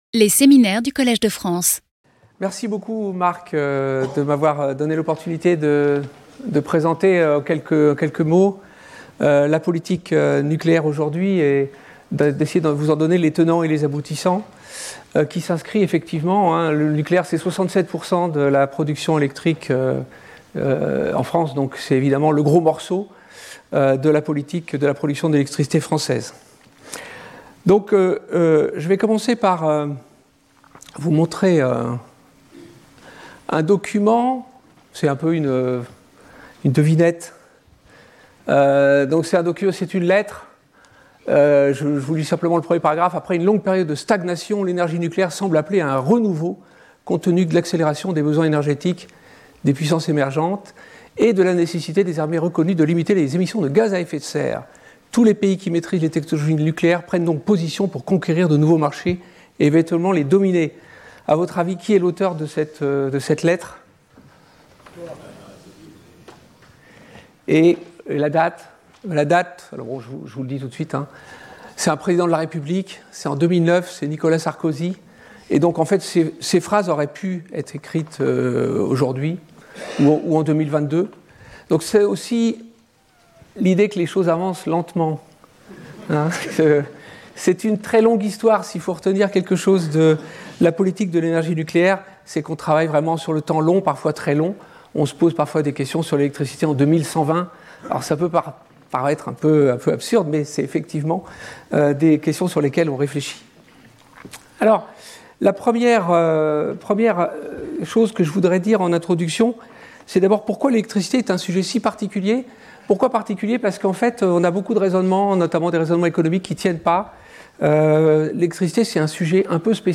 Intervenant(s) Vincent Berger Haut-Commissaire à l’énergie atomique
Séminaire